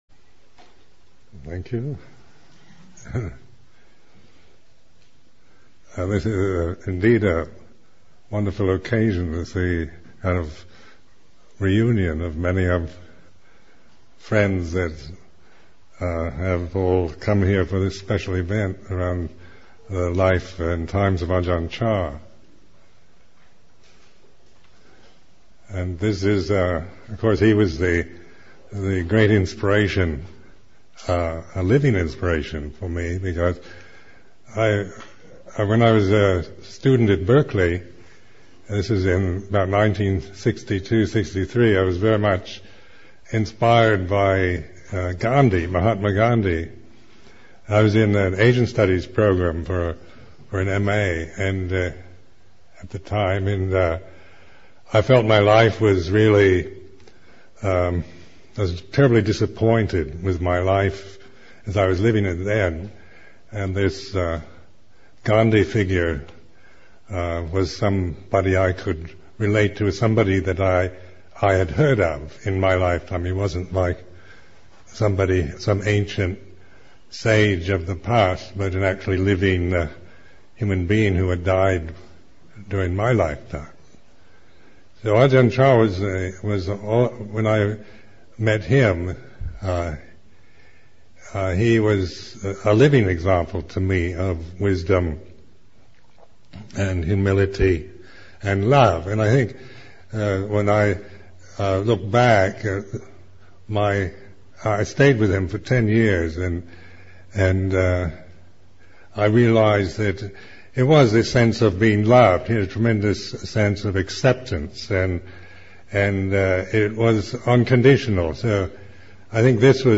Remembering Ajahn Chah Weekend, Session 4 – Apr. 28, 2001
2. The inspiration of Ajahn Chah. Teaching by Ajahn Sumedho.